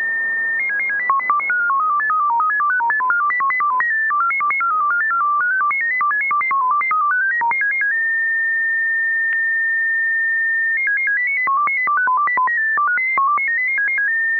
SERDOLIK 34-TONE MFSK
34-tone MFSK in "chat-mode" sending plain text op-msgs with 10 Bd